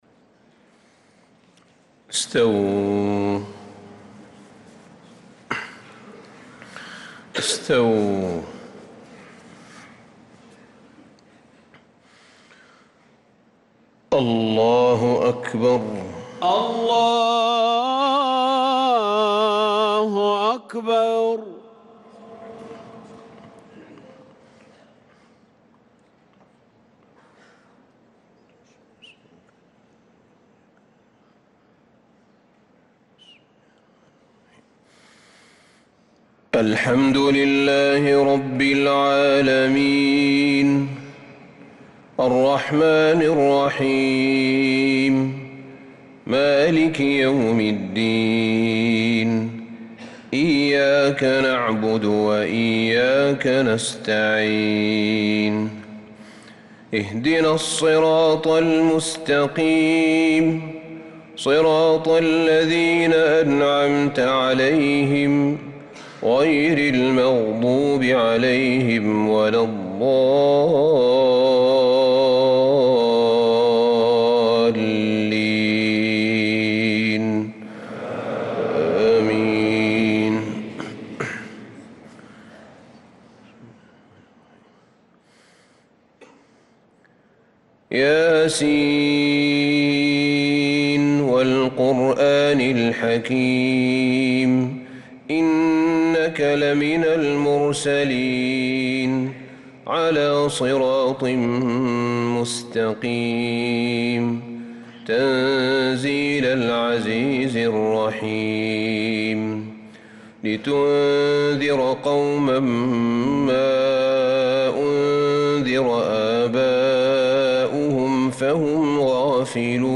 صلاة الفجر للقارئ أحمد بن طالب حميد 20 ربيع الآخر 1446 هـ
تِلَاوَات الْحَرَمَيْن .